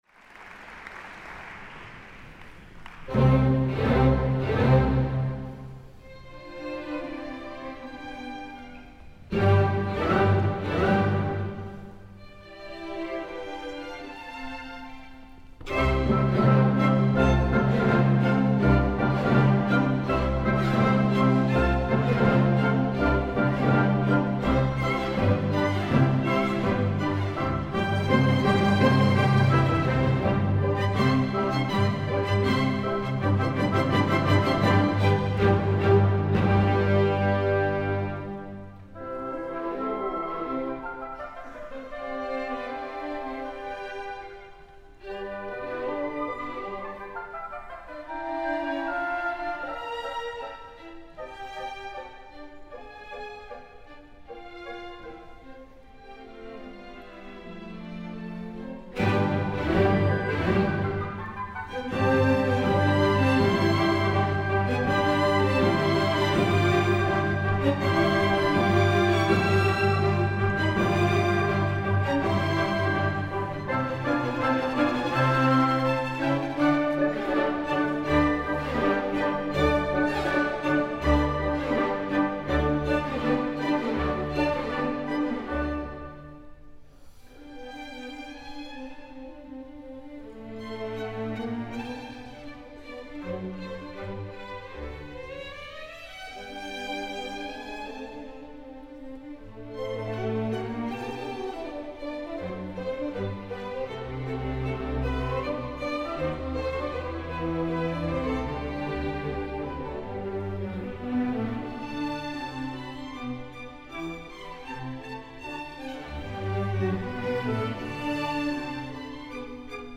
Wolfgang Amadeus Mozart: Sinfonía nº 41 en do mayor, K.551 "Jupiter" | Basque National Orchestra - Euskadiko Orchestra
Saison Symphonique